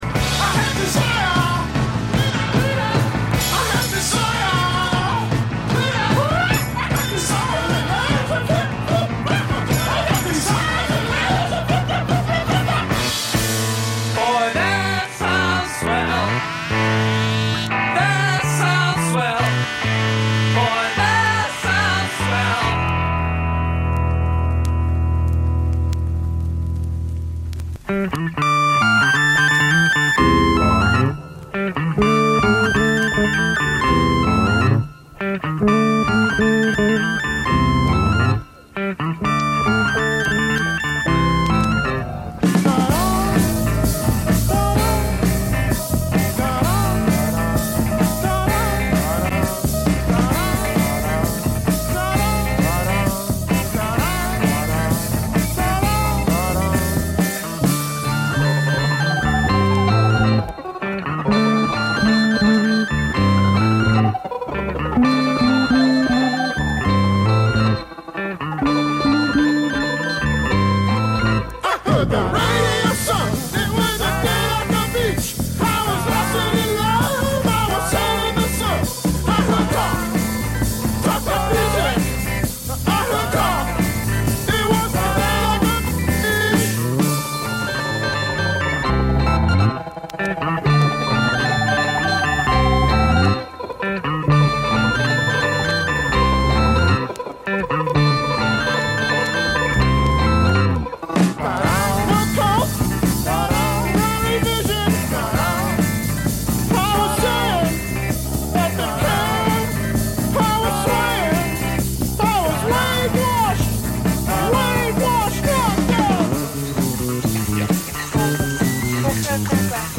Schr�ge Originale stehen noch schr�geren Coverversionen gegen�ber.
So entdecken wir f�r unsere H�rer musikalische Grausamkeiten genauso, wie "Unerh�rtes" von einst und heute. Wir w�nschen gute Schallplattenunterhaltung und guten Empfang.